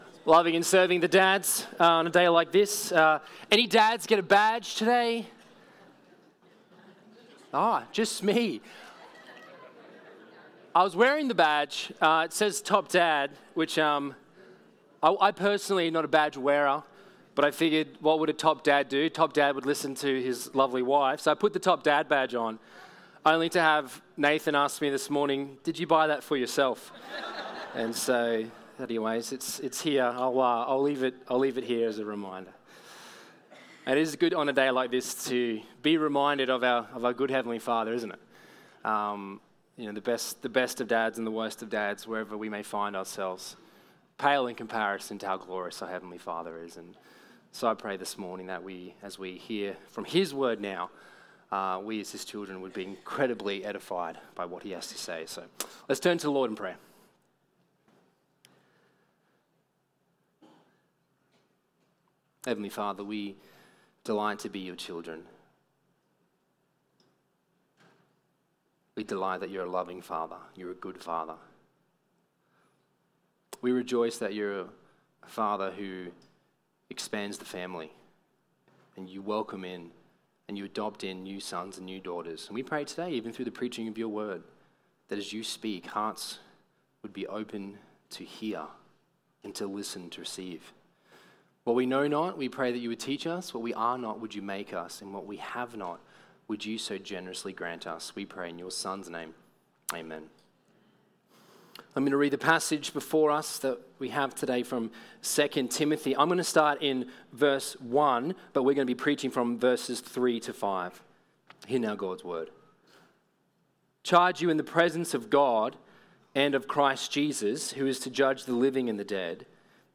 Sermons | Coomera Baptist Church